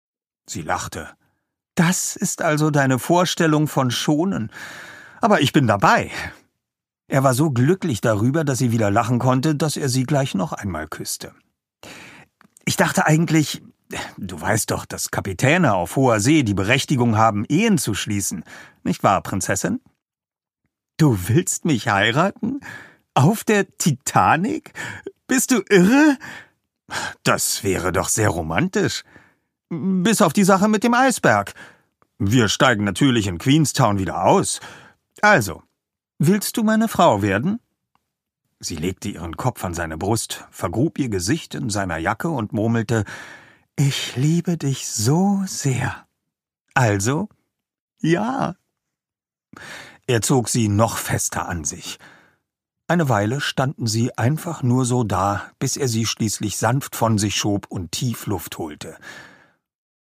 Die Edelstein-Trilogie, Band 1 (Ungekürzte Lesung)
Der Name de Villiers wird in Übereinstimmung mit Kerstin Gier in diesem Hörbuch korrekt englisch ausgesprochen, da es sich zwar ursprünglich um ein französisches Geschlecht handelte, der englische Zweig der Familie aber mittlerweile englisch ausgesprochen wird.